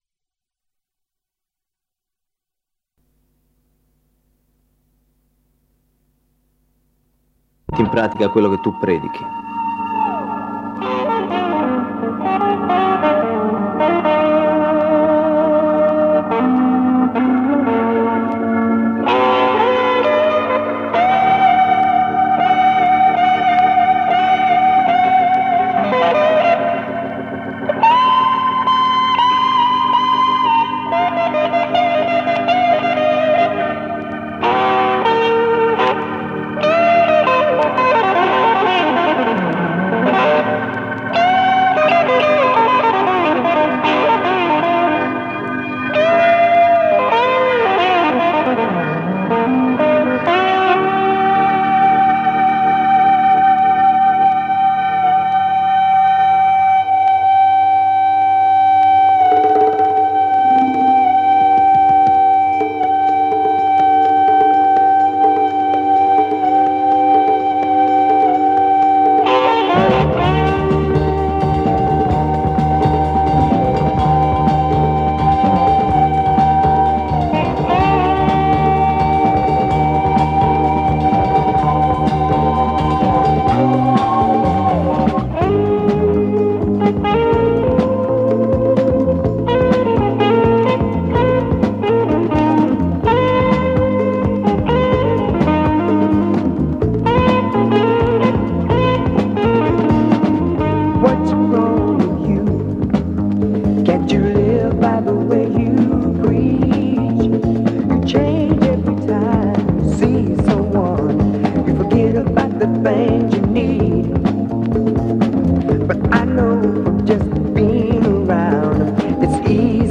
Conduttore Carlo Massarini.